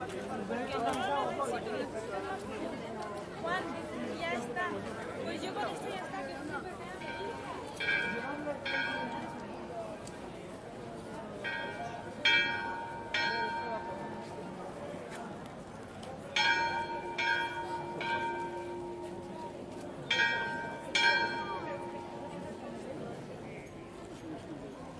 描述：在Baiona（Pontevedra）的Madres Dominicas修道院录制Angelus的铃声。2015年8月15日，18：53：53.MS侧微观水平：角度幅度为90度。
Tag: 铃铛 巴约纳 修道院 振铃换金钟 VAL-Minhor Madres-Dominicas 现场录音